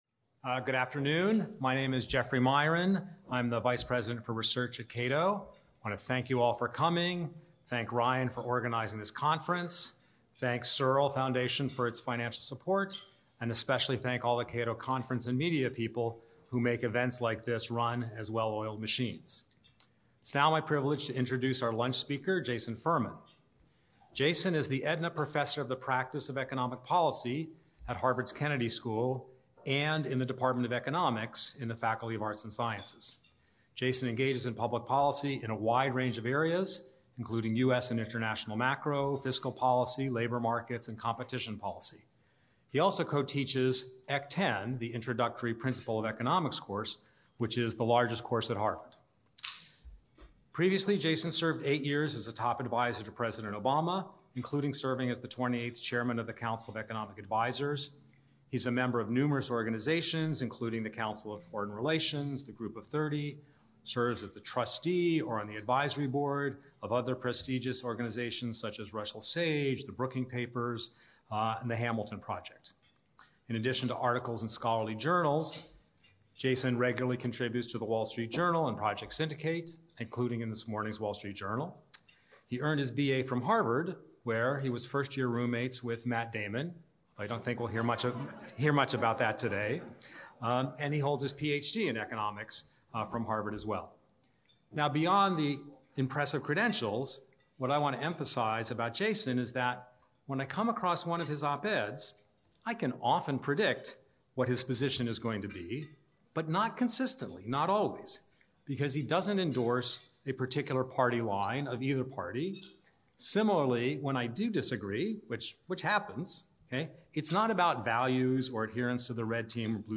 New Challenges to the Free Economy (from Left and Right): Luncheon Address